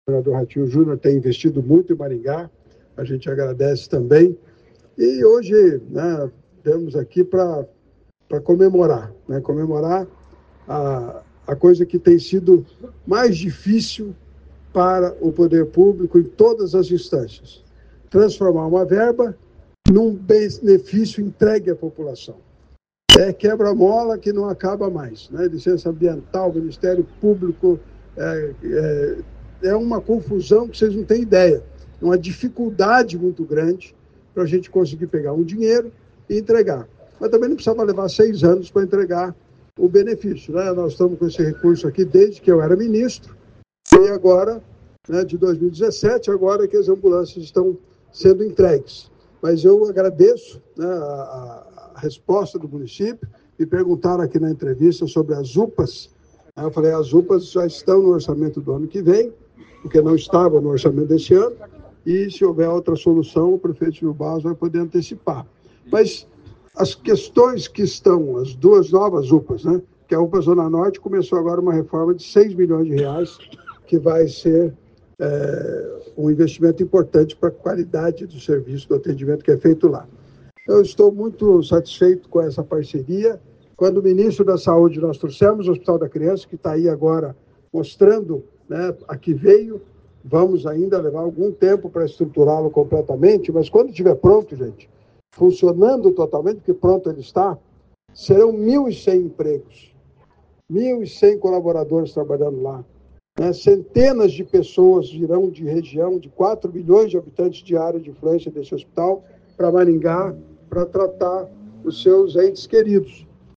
Ouça o que diz o deputado federal Ricardo Barros: